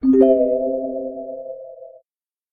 Samsung Galaxy Bildirim Sesleri - Dijital Eşik